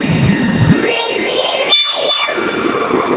WINDTEXTUR-L.wav